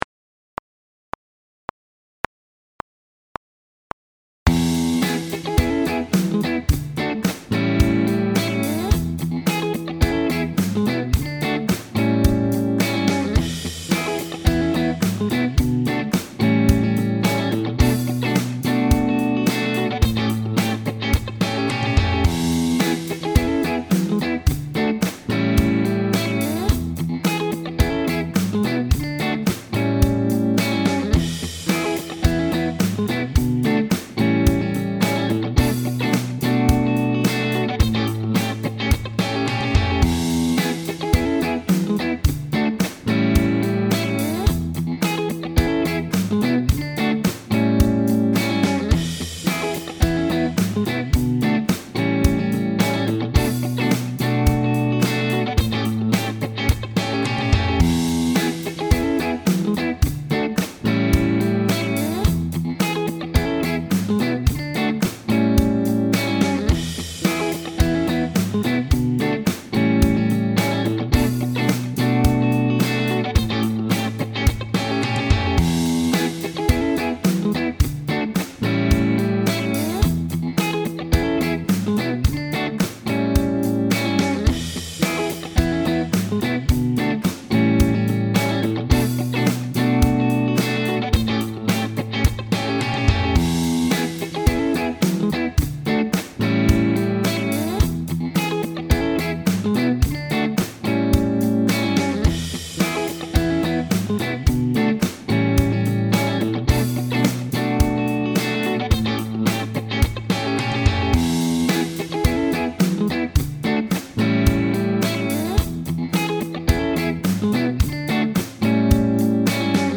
This one uses the chords of E, D, A7 & Bm – try using E major or minor pentatonic scales, or E mixolydian & dorian modes for some extra colour.